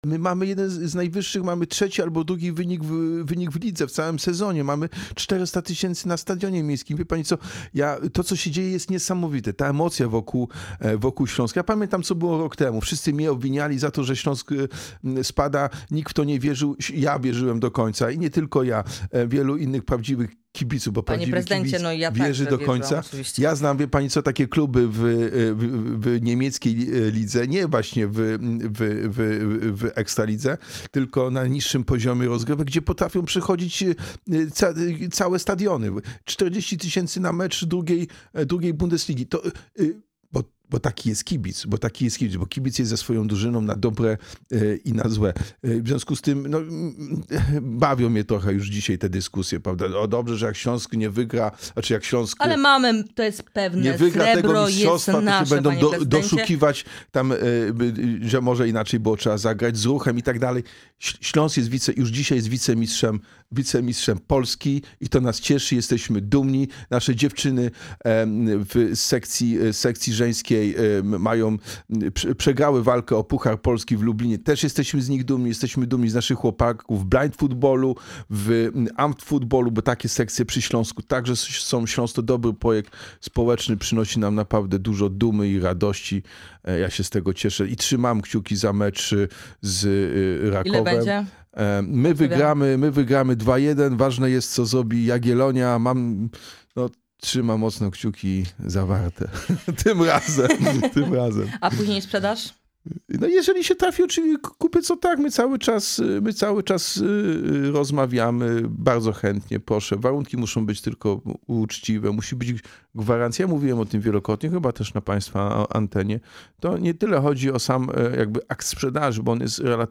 W audycji „Poranny Gość” poruszyliśmy także temat Śląska Wrocław.